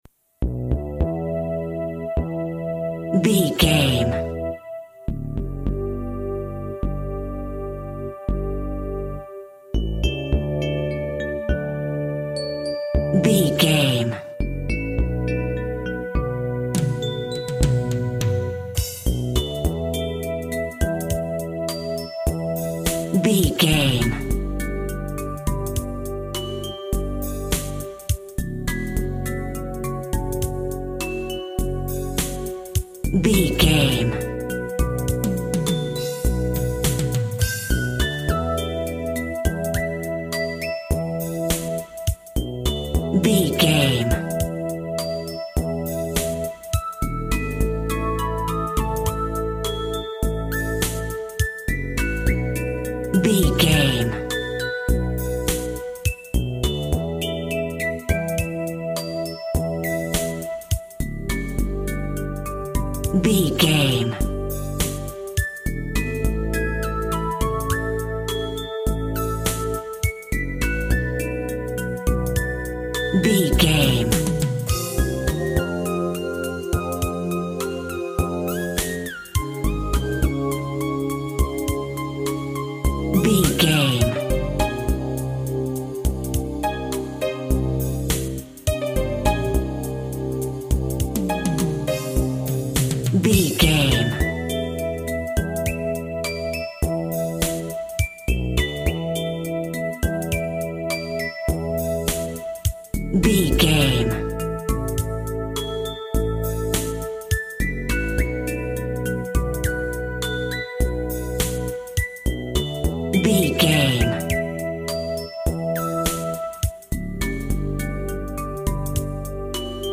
Aeolian/Minor
calm
repetitive
soft
synthesiser
drum machine
electric piano
electronic
80s music
synth bass
synth lead